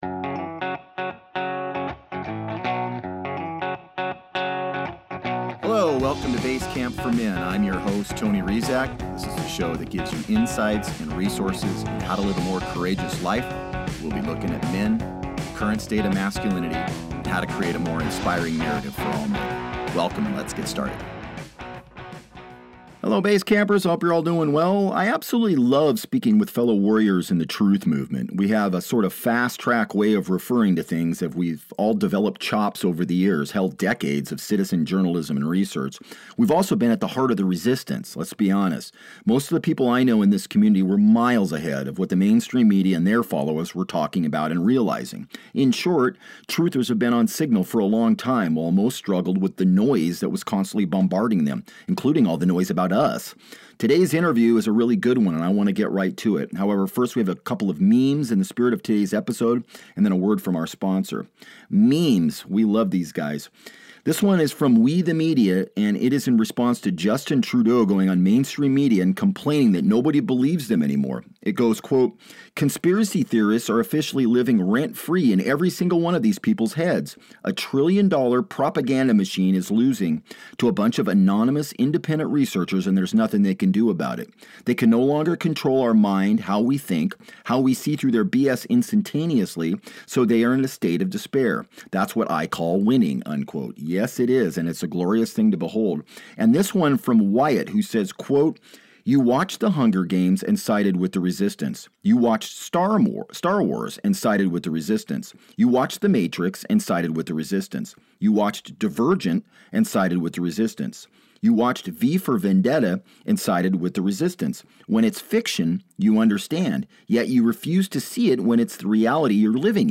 Join Basecamp as they sit around the fire with 2 x mayor Reinette Senum. They discuss 13 audacious predictions for 2024.